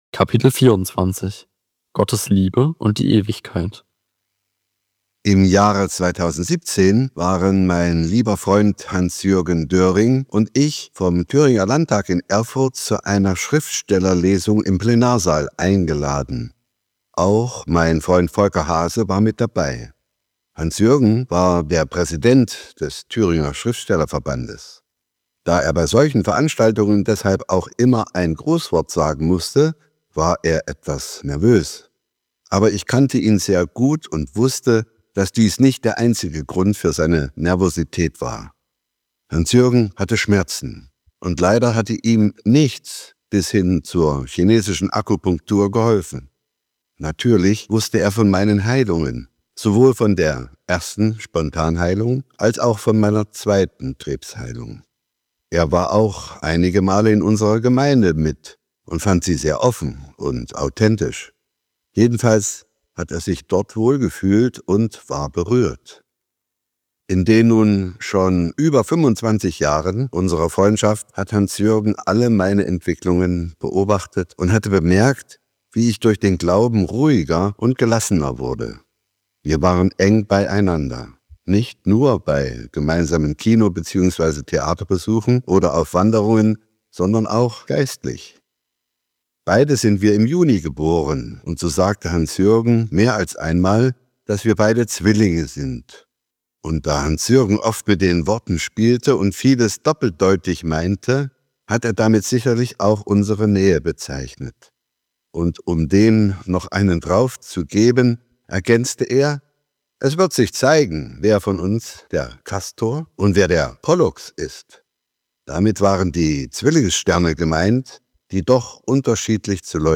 SIEHE GALERIEBILDER Hörbuch-Download Format